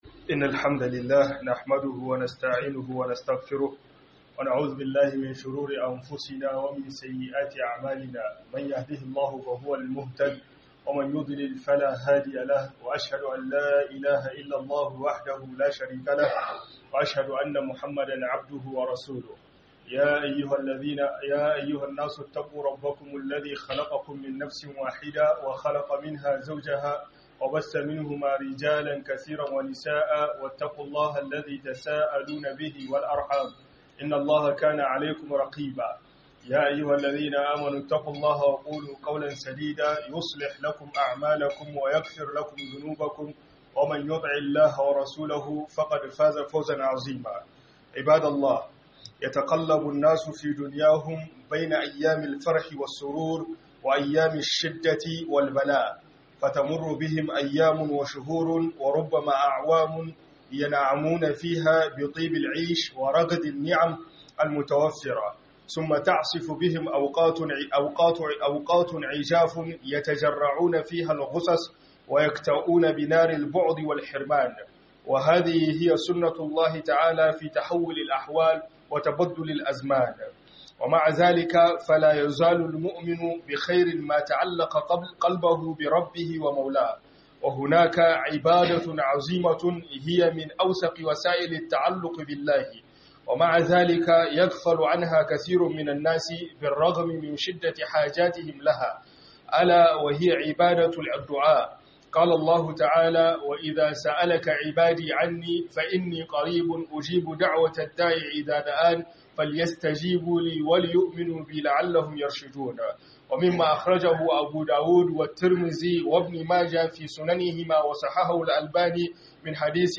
ADDU'A ITA CE IBADA - HUDUBA